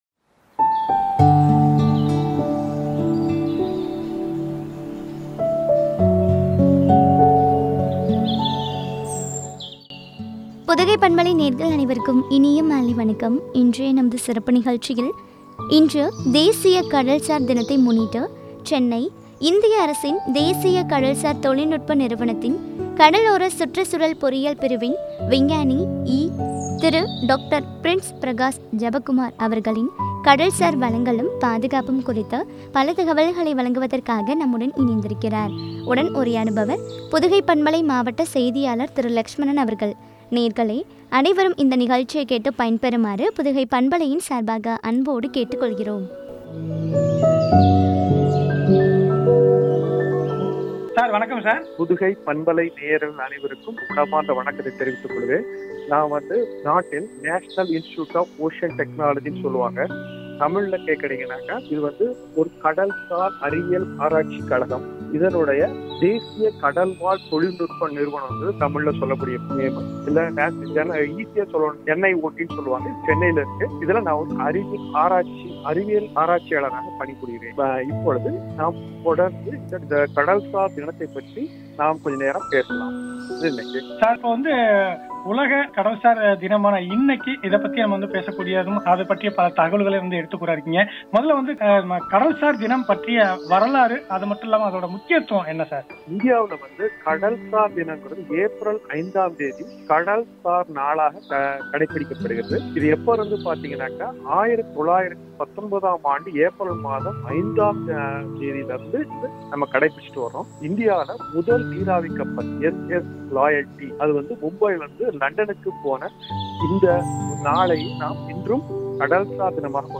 கடல்சார் வளங்களும், பாதுகாப்பும் பற்றிய உரையாடல்.